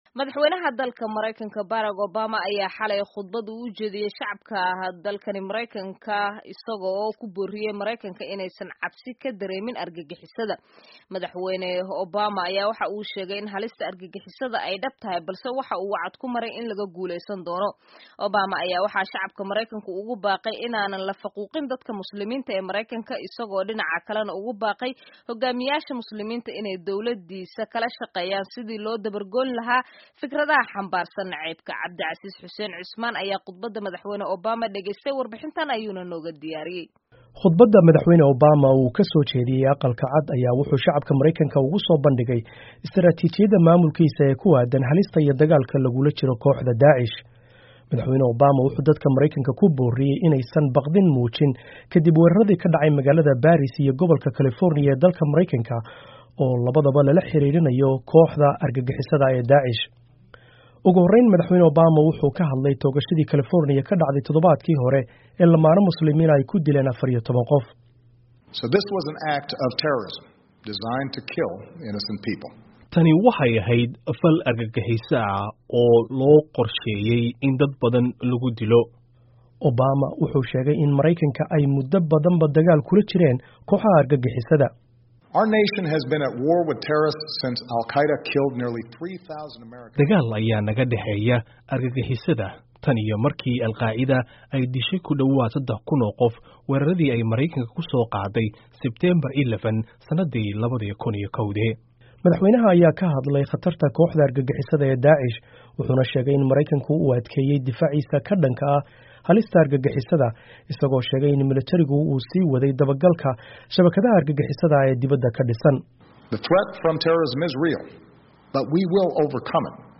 Dhageyso Warbixinta Khudbadda Obama